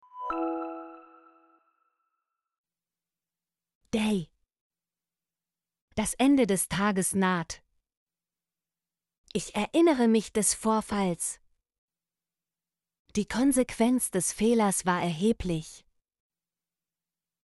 des - Example Sentences & Pronunciation, German Frequency List